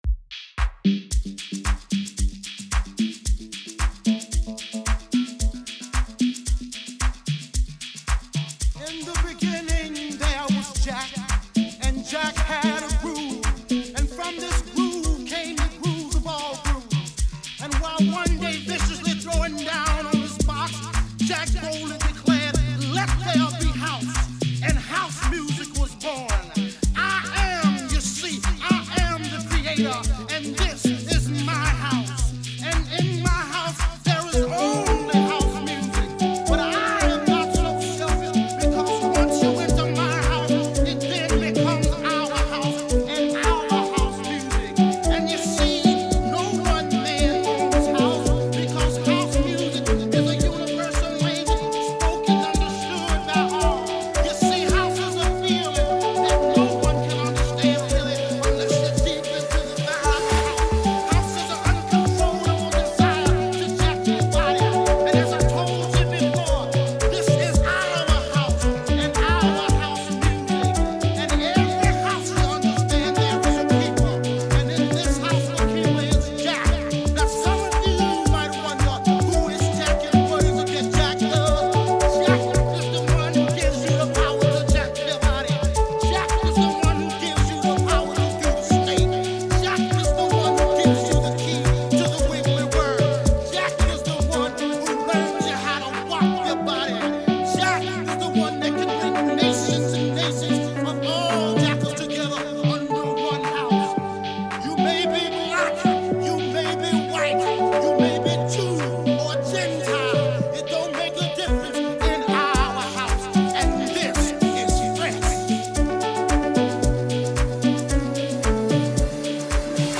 04:51 Genre : Amapiano Size